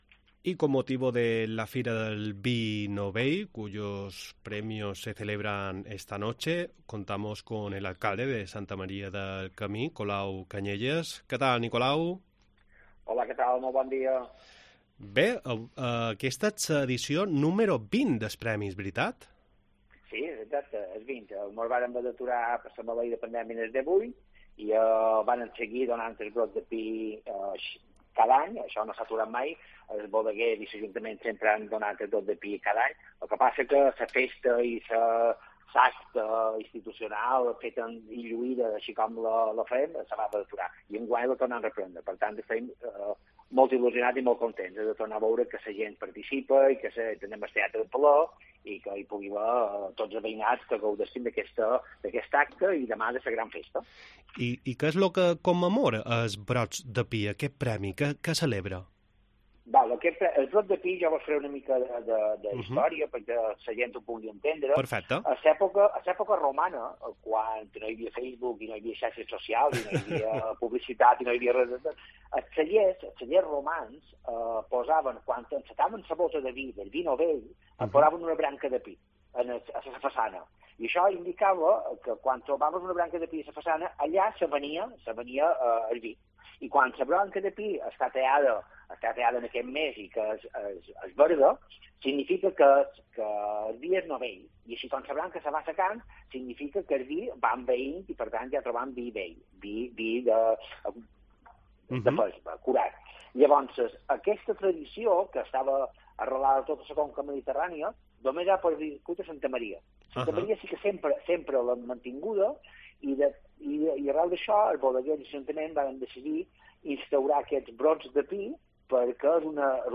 Colau Canyelles, alcande de Santa Maria del Camí, ha hablado para La Mañana en Cope sobre els Brots de Pi, una tradicción que proviene de la antigua roma y que cuenta con miles de años.